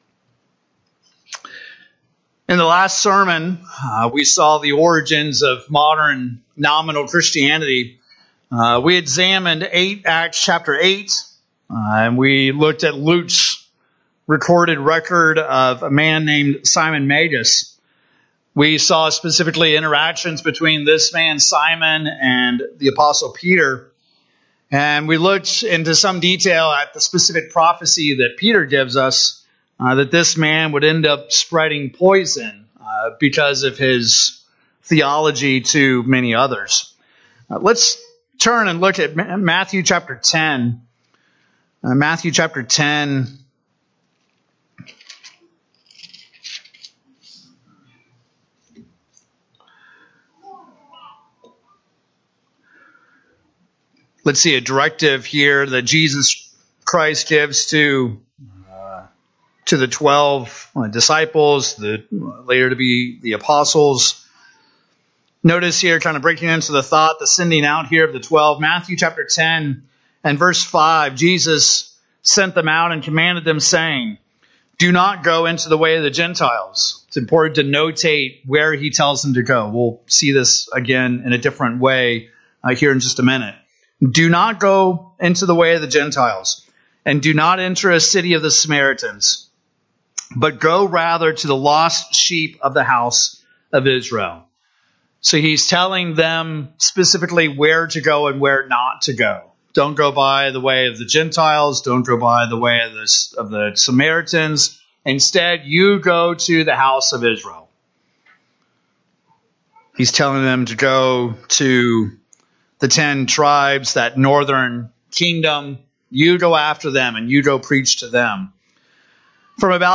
In this sermon we examine the apostles interaction with Simon Magus' counterfeit Christian church. Let's notice their encouragement toward the true church to remain faithful, while battling with veracity against the nominal Christianity of their (and our) day.